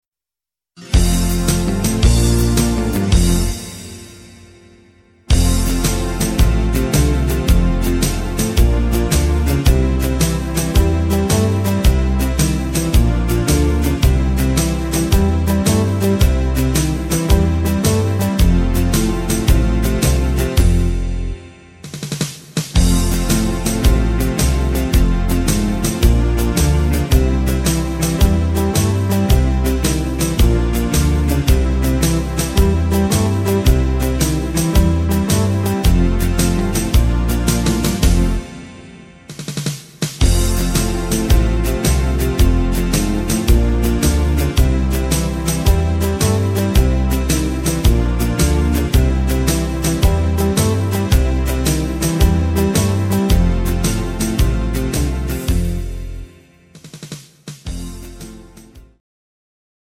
instr. Saxohon